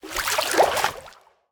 sounds / liquid / swim6.ogg
swim6.ogg